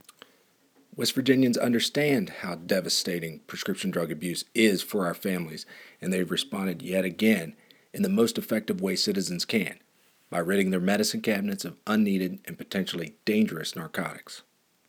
Listen to an audio clip from U.S. Attorney Booth Goodwin discussing the Take-Back results by